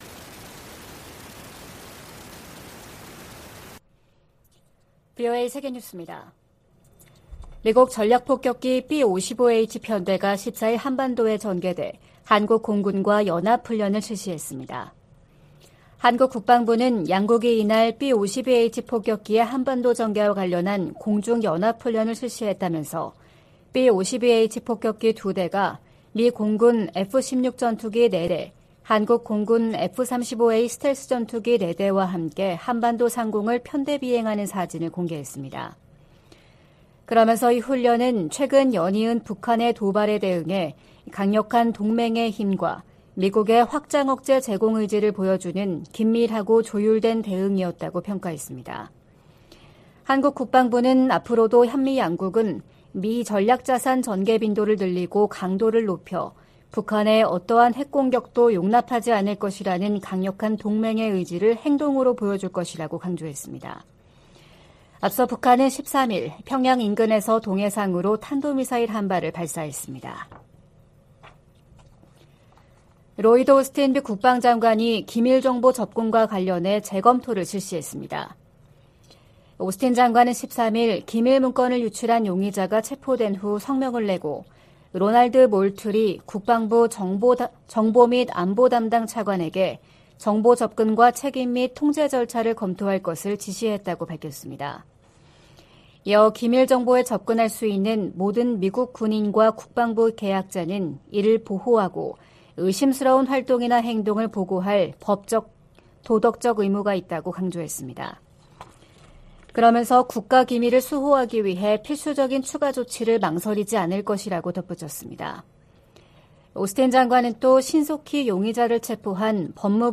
VOA 한국어 '출발 뉴스 쇼', 2023년 4월 15일 방송입니다. 북한은 13일 발사한 '화성포-18형'이 고체연료를 사용한 신형 대륙간탄도미사일(ICBM)이라고 다음날인 14일 밝혔습니다. 북한이 핵 공격을 감행하면 김정은 정권의 종말을 초래할 것이라고 미국과 한국 국방당국이 경고했습니다. 북한이 우주 사업을 적극 추진하겠다고 밝힌 데 대해 미 국무부는 안보리 결의 위반 가능성을 지적했습니다.